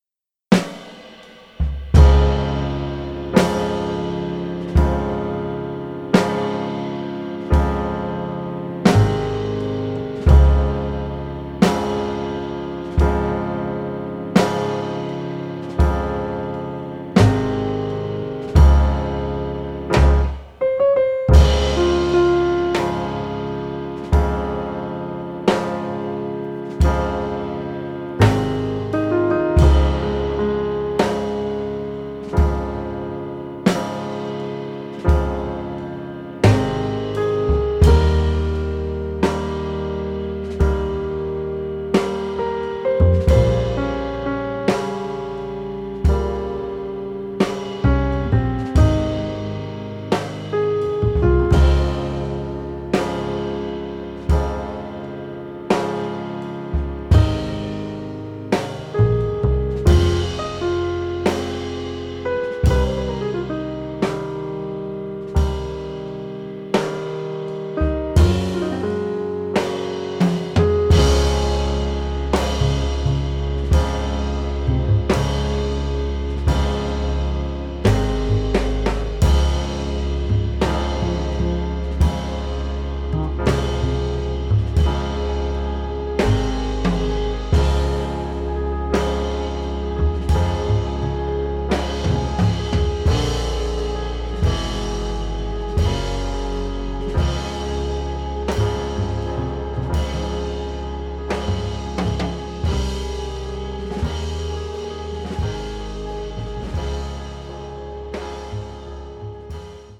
their brand of the form laced with pop and jazz